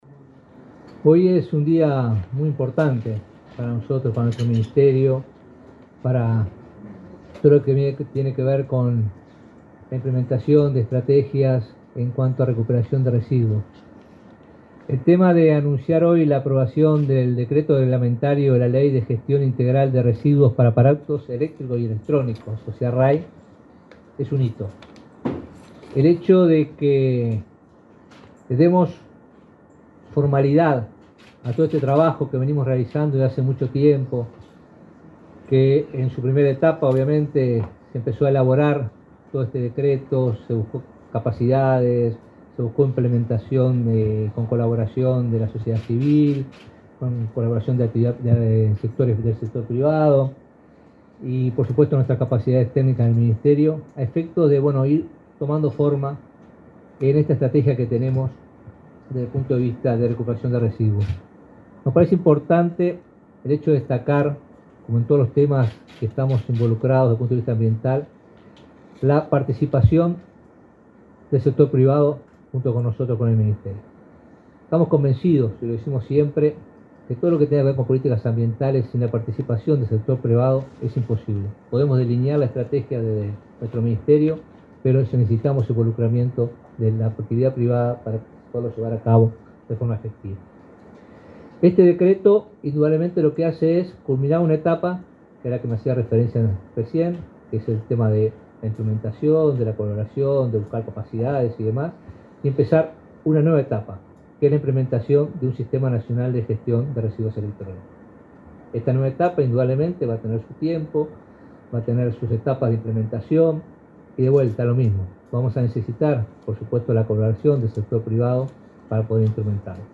Palabras del ministro de Ambiente, Robert Bouvier